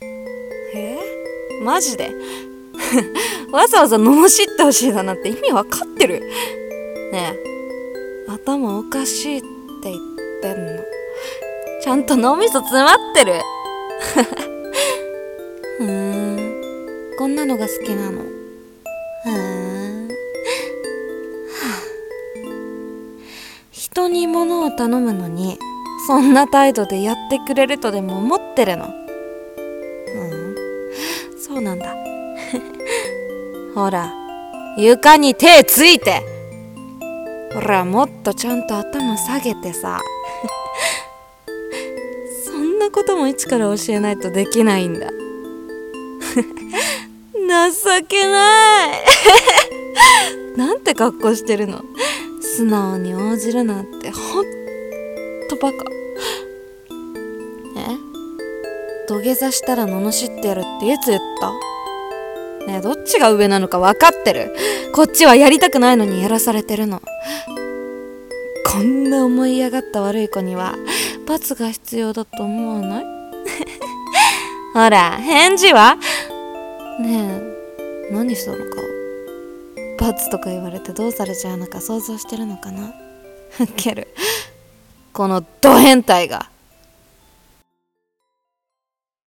1人声劇『罵倒してってお願いしたら…という話』